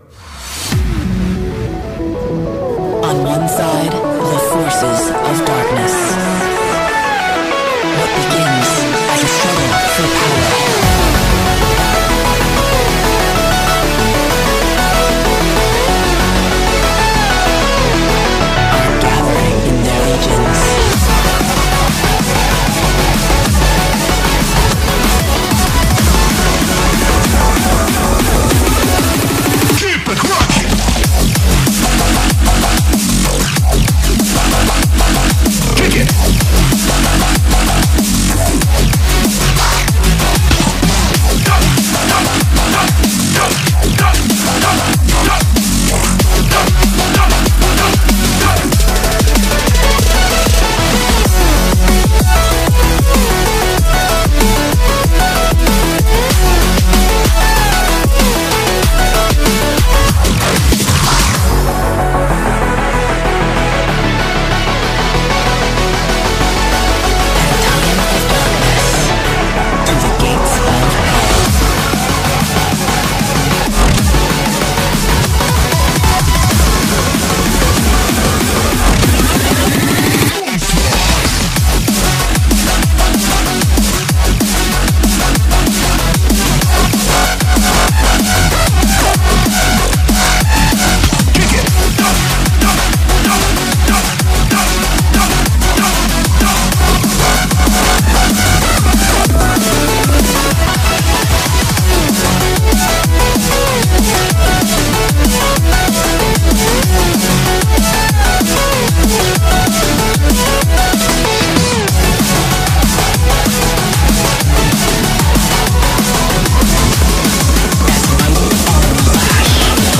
BPM95-190